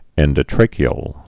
(ĕndə-trākē-əl)